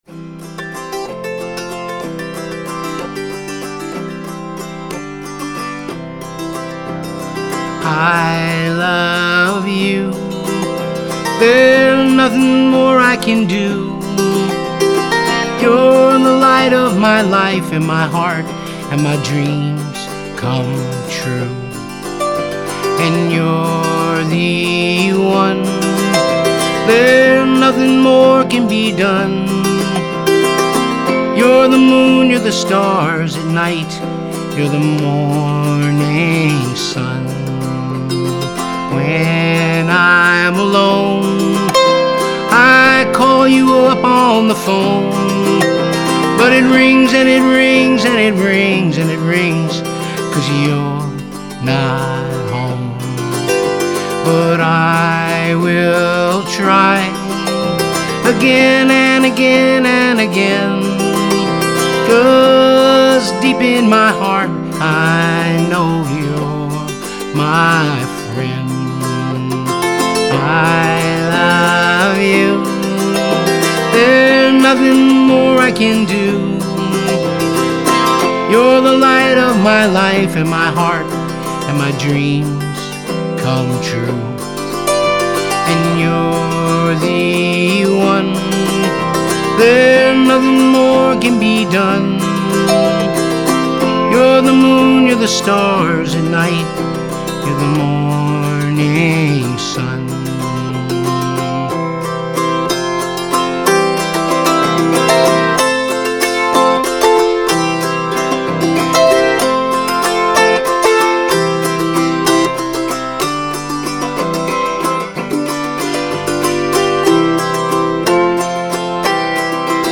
Recorded at Clinton Studios New York City